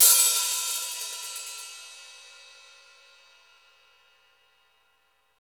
CYM X14 HA0D.wav